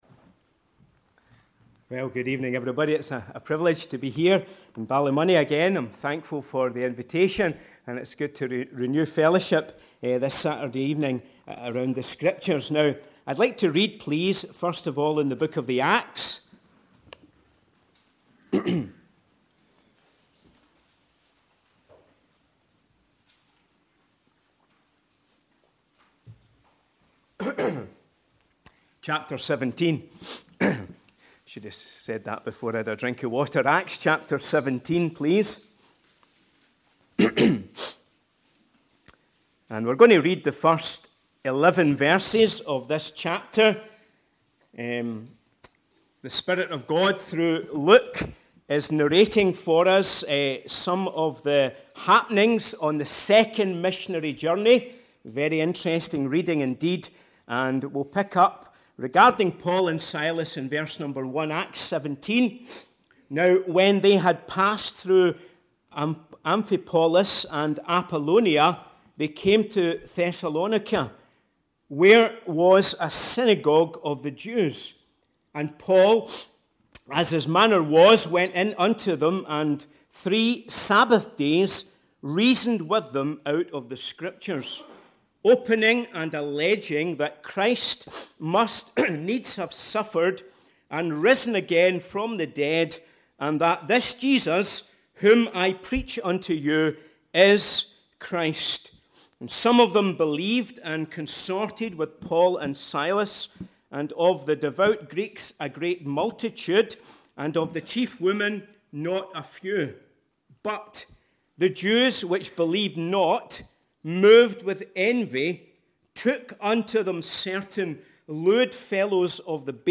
Meeting Type: Ministry